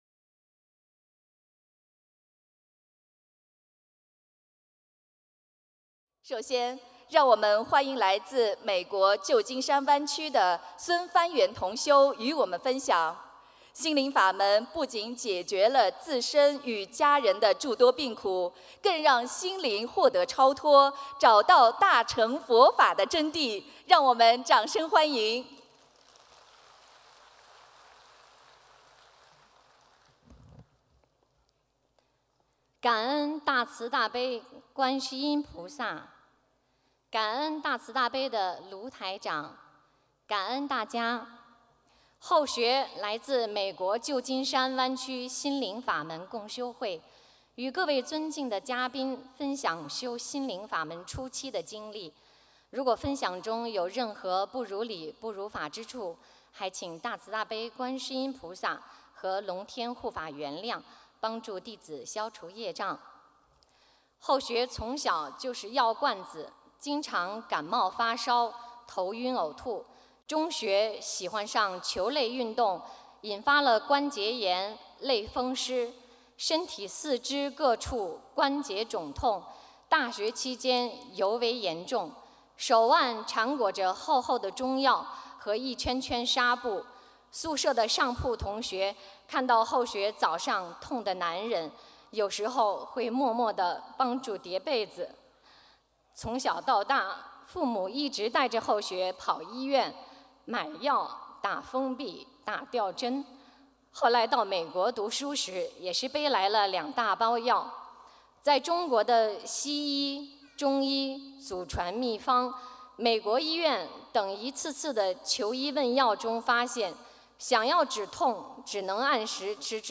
美國洛杉矶【同修分享